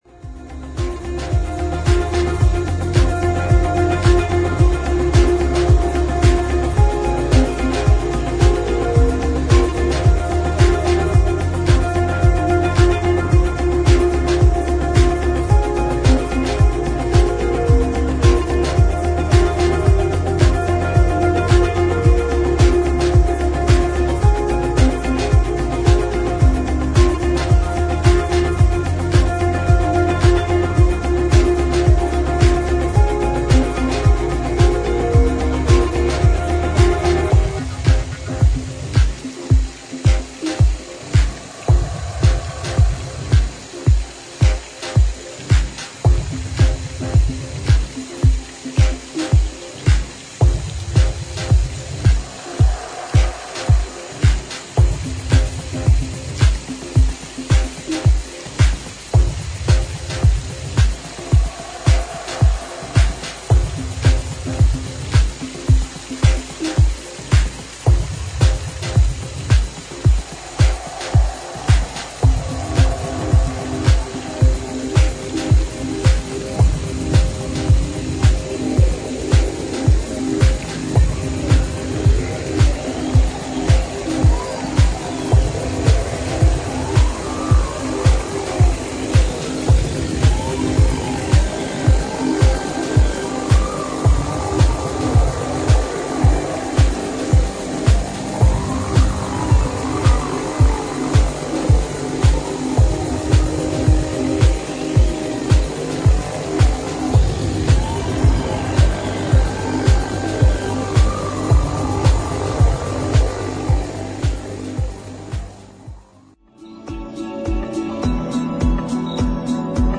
アンビエス感溢れるフローティンなバレアリック・ハウス
サイケデリック/シューゲイザーなどを内包した哀愁のある旋律に微睡む